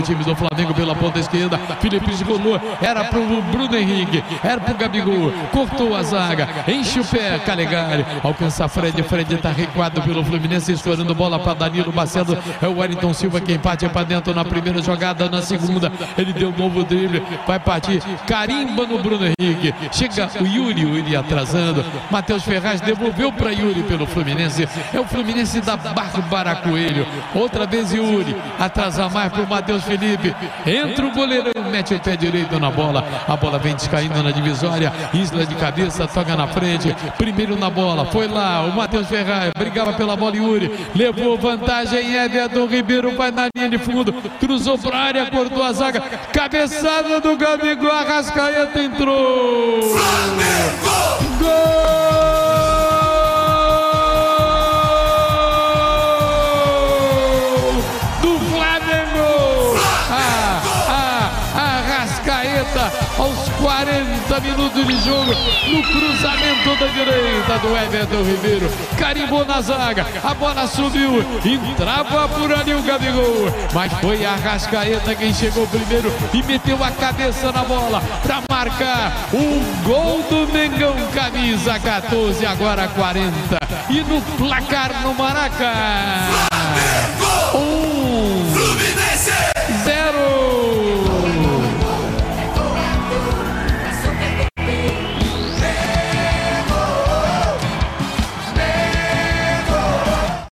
Ouça os gols da vitória do Fluminense sobre o Flamengo com a narração de José Carlos Araújo